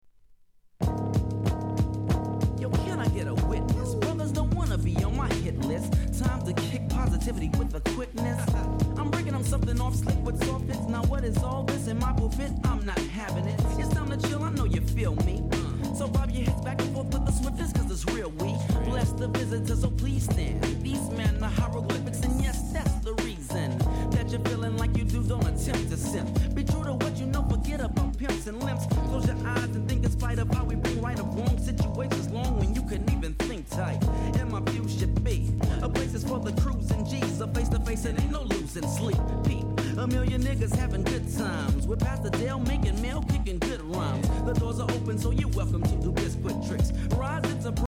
両面共に激ダウナーなHIP HOPです!